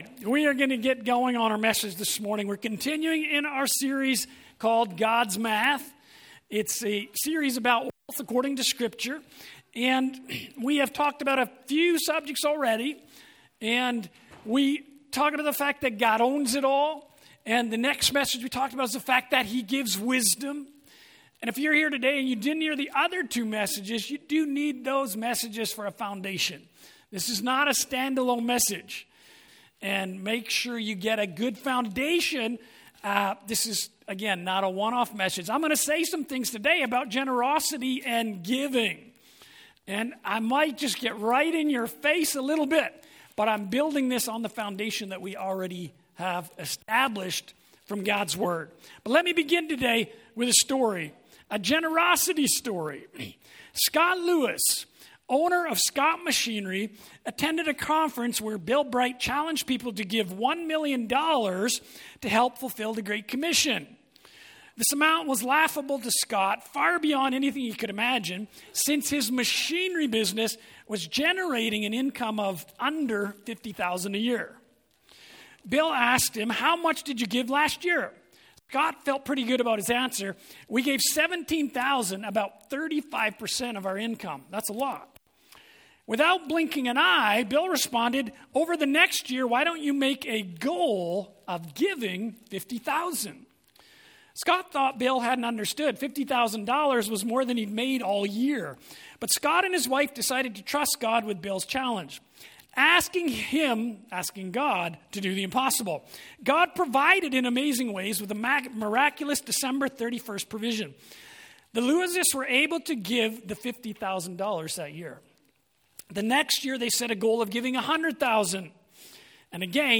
Sermons | Abundant Life Worship Centre